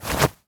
foley_object_throw_move_04.wav